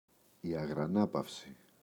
αγρανάπαυση, η [aγraꞋnapafsi]